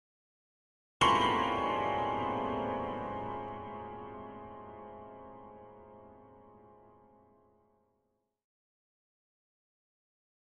Piano High Tension Chord 1